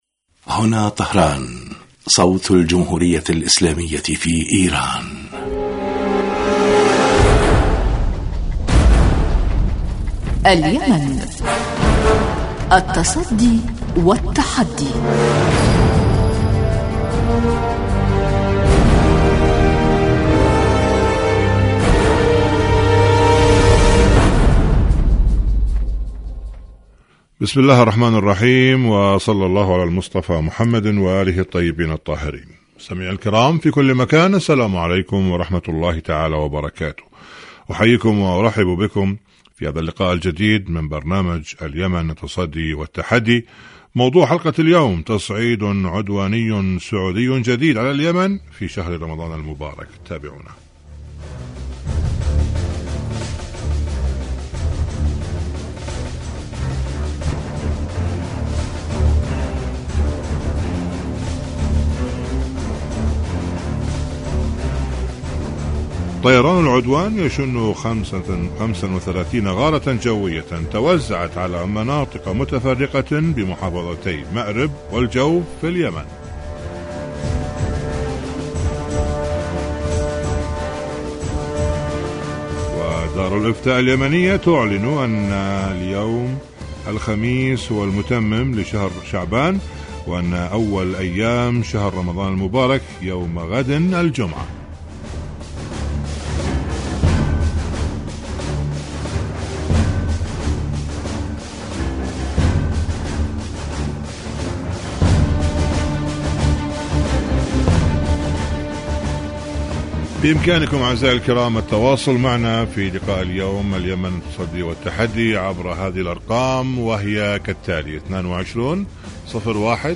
برنامج سياسي حواري يأتيكم مساء كل يوم من إذاعة طهران صوت الجمهورية الإسلامية في ايران .
البرنامج يتناول بالدراسة والتحليل آخر مستجدات العدوان السعودي الأمريكي على الشعب اليمني بحضور محللين و باحثين في الاستوديو.